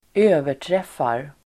Uttal: [²'ö:ver_tref:ar]